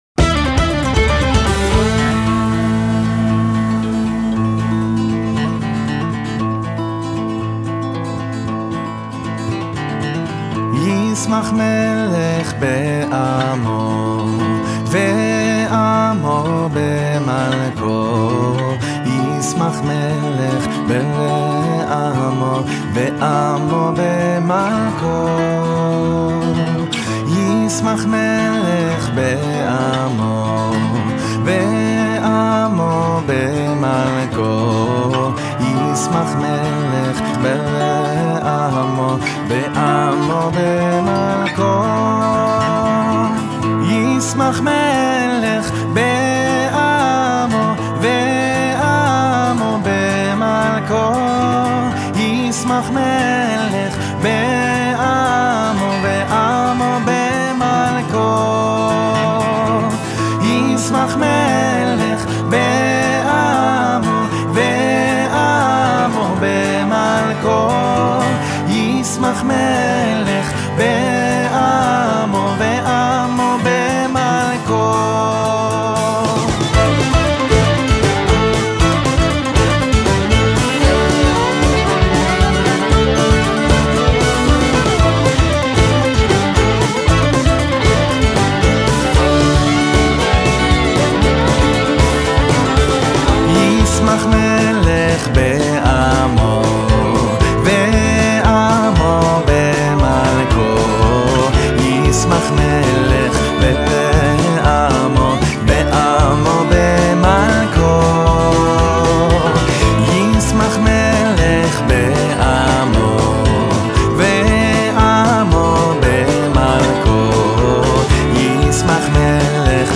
בליווי הקלידן